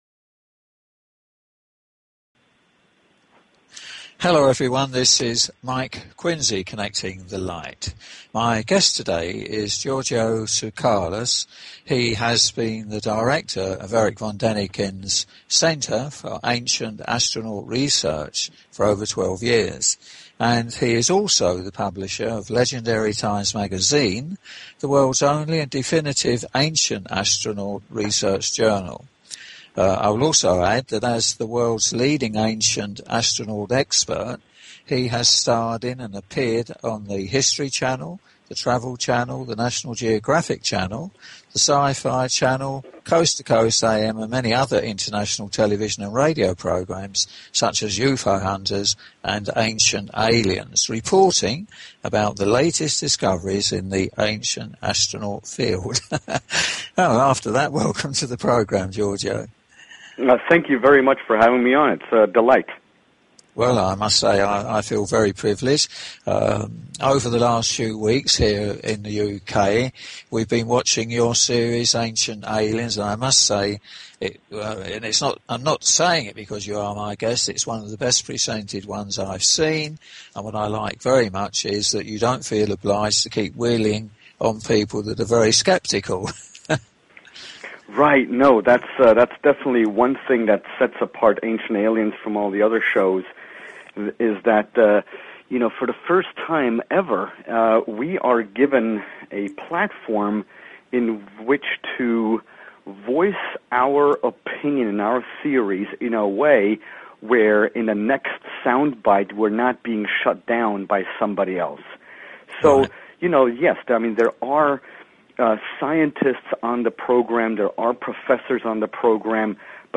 Talk Show Episode, Audio Podcast, Connecting_The_Light and Courtesy of BBS Radio on , show guests , about , categorized as
My guest was Giorgio A. Tsoukalos the star and Consulting Producer of Ancient Aliens - The Series (2010), and publisher of Legendary Times Magazine, the only Ancient Astronaut publication in the world.